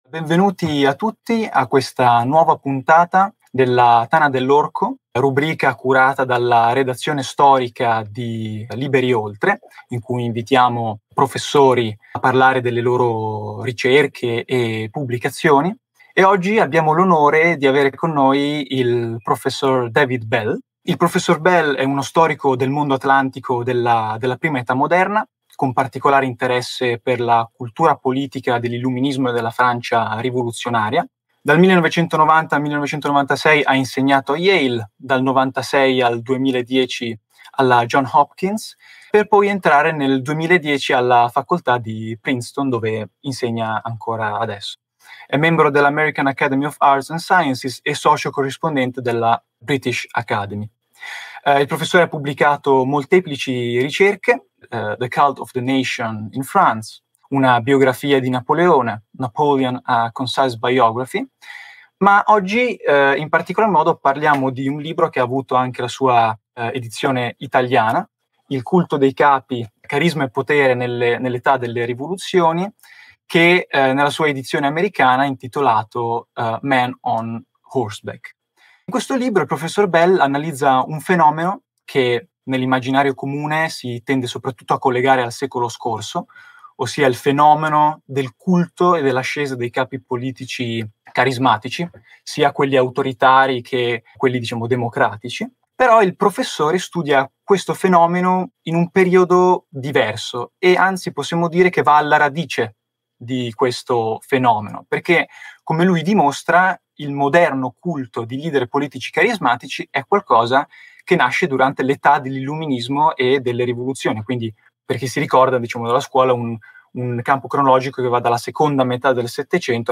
SOTTOTITOLI IN ITALIANO | In questa puntata parliamo con il professor David Bell di una delle sue ultime importanti pubblicazioni - Il culto dei capi: Carisma e potere nell'età delle rivoluzioni. In Europa come in America, l’esperienza rivoluzionaria portò spesso leader militari carismatici ad assumere il potere supremo.